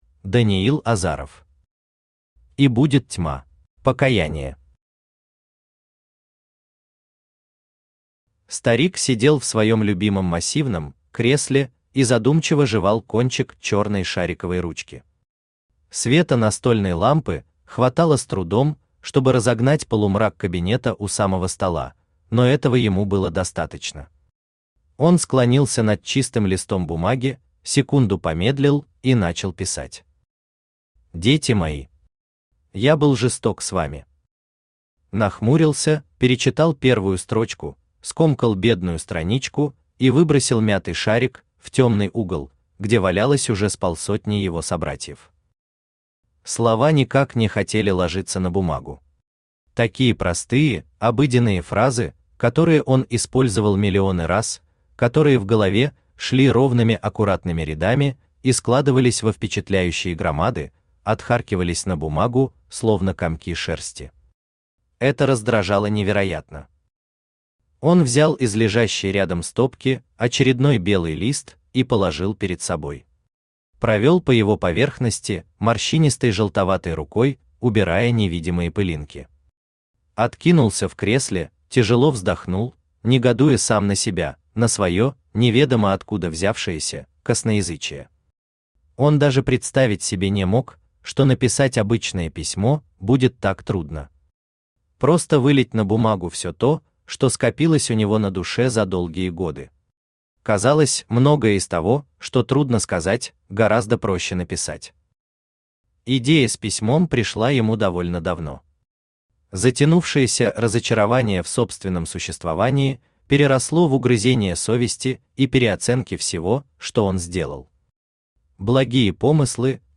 Аудиокнига И будет тьма | Библиотека аудиокниг
Aудиокнига И будет тьма Автор Даниил Азаров Читает аудиокнигу Авточтец ЛитРес.